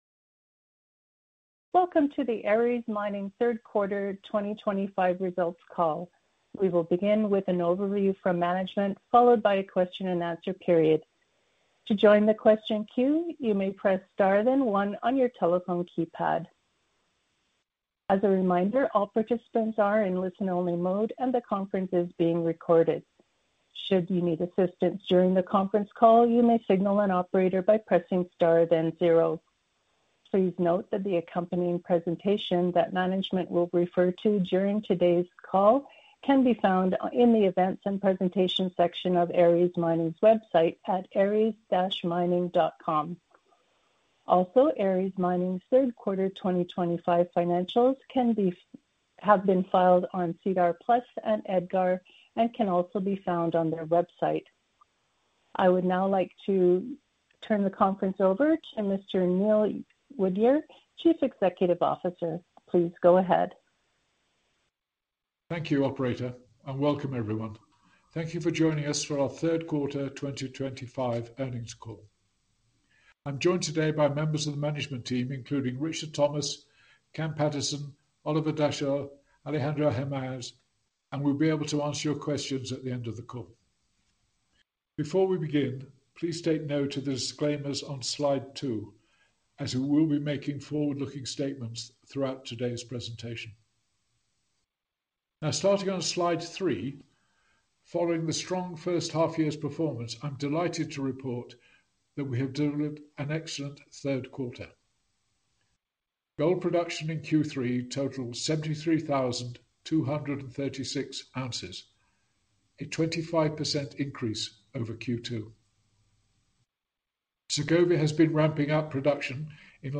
Q3 2025 Results Conference Call
Aris-Mining-Q3-2025-Earnings-Call-Recording.mp3